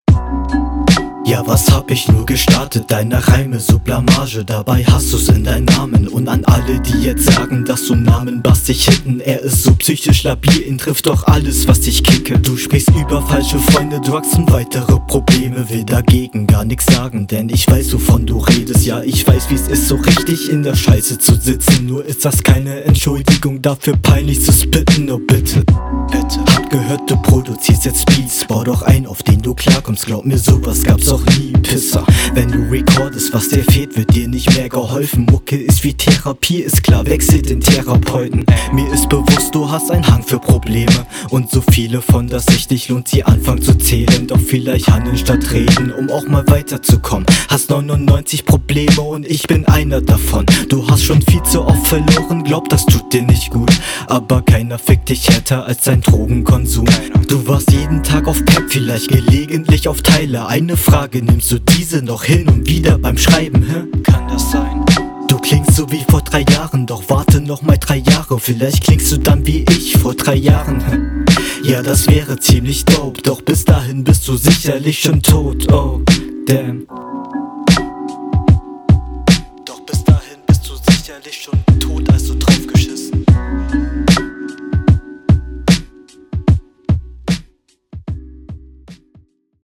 Flow ist sehr clean wieder, auch schöne fronts
Stimme cool, Flow monoton, vor allem auf eineinhalb Minuten, Inhalt passabel